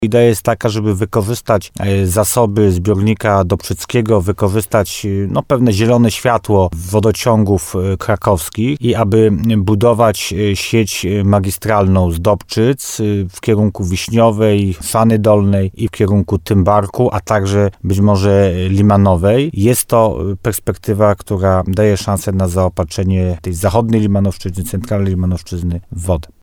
Gospodarzem spotkania był wójt gminy Tymbark Paweł Ptaszek. Jak mówił w programie Słowo za Słowo na antenie radia RDN Nowy Sącz, coraz większe problemy z wodą zmuszają samorządy z regionu do poszukiwania długofalowego rozwiązania.
Rozmowa z Pawłem Ptaszkiem: Tagi: Słowo za Słowo Limanowa woda susza gmina Tymbark Paweł Ptaszek Tymbark zbiornik dobczycki